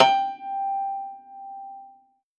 53v-pno09-G3.wav